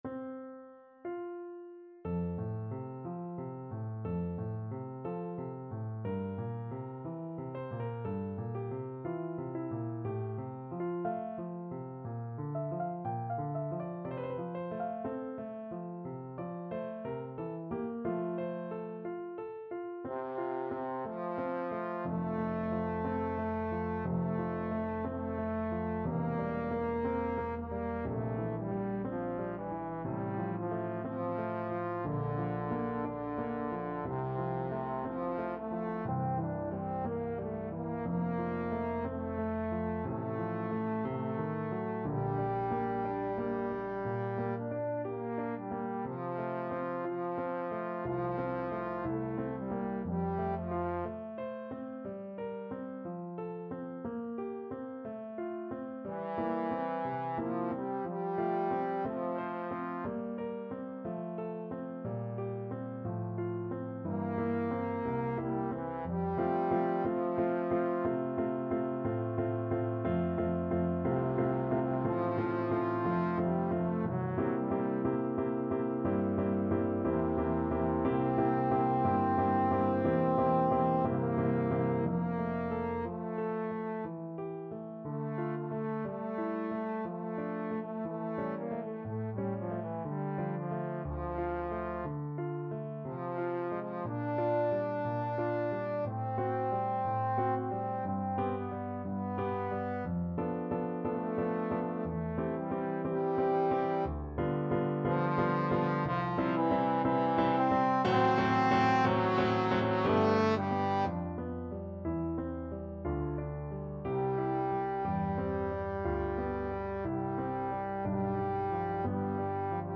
Classical Beethoven, Ludwig van Adelaide, Op.46 Trombone version
Play (or use space bar on your keyboard) Pause Music Playalong - Piano Accompaniment Playalong Band Accompaniment not yet available transpose reset tempo print settings full screen
Trombone
~ = 60 Larghetto
F major (Sounding Pitch) (View more F major Music for Trombone )
4/4 (View more 4/4 Music)
Classical (View more Classical Trombone Music)